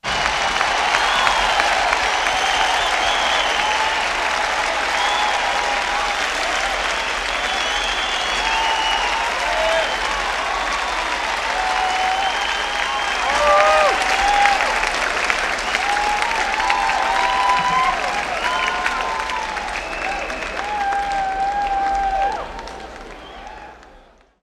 APLAUSOS ESTADIO
Tonos gratis para tu telefono – NUEVOS EFECTOS DE SONIDO DE AMBIENTE de APLAUSOS ESTADIO
Ambient sound effects
aplausos_estadio.mp3